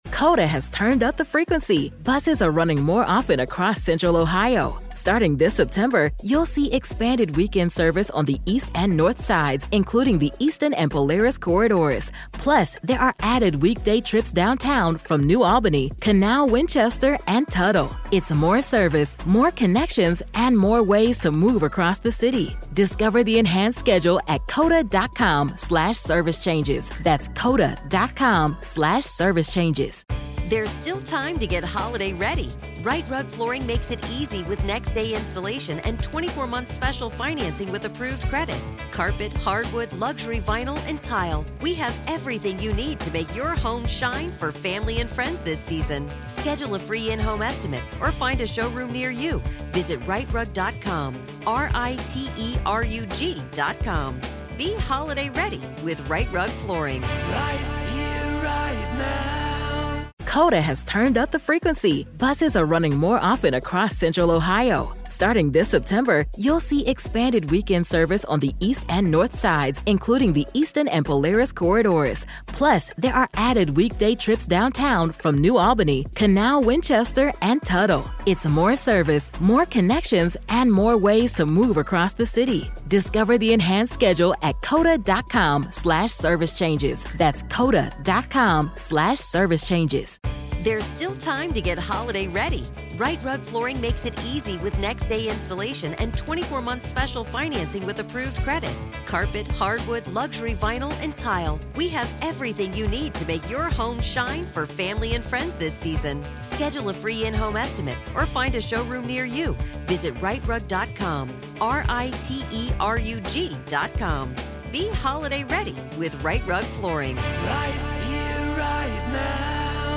Together, we explore the tragedies that shaped the city and the hauntings that linger long after the headlines ended. This is Part Two of our conversation.